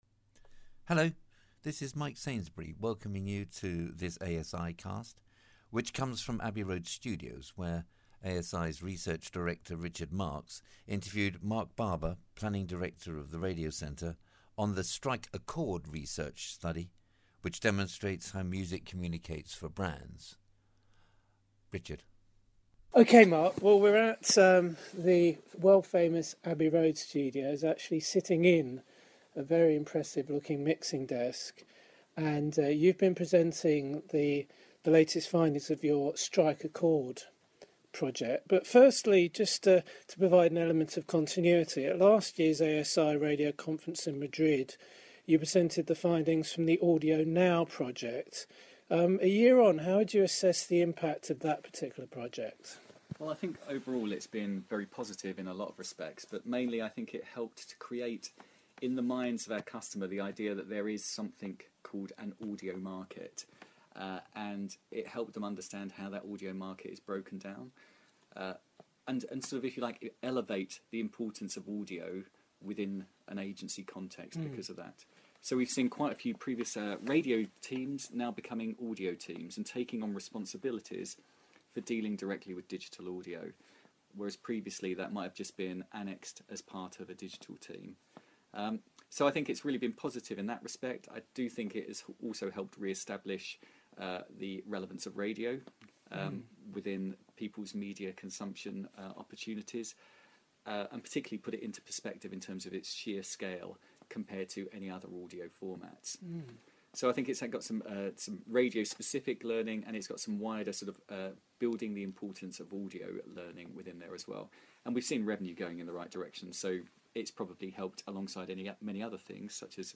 asiCast 3 Live from Abbey Road – Interview